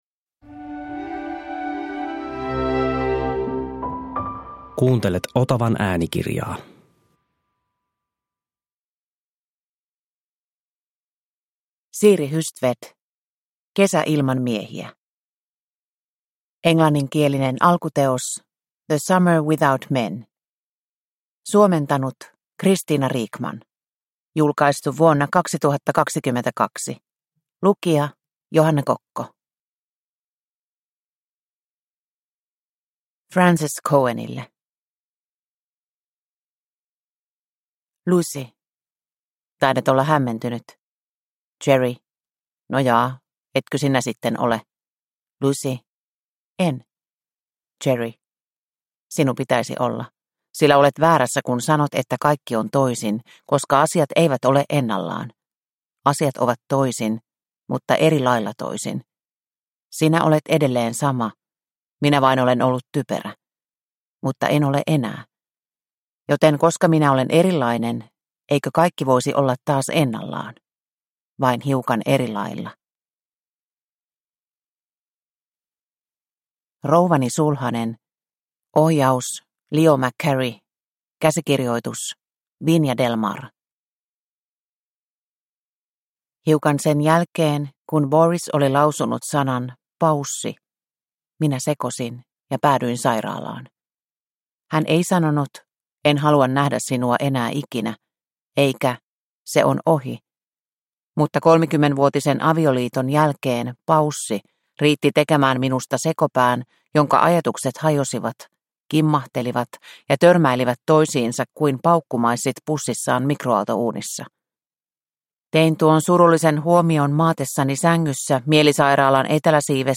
Kesä ilman miehiä – Ljudbok – Laddas ner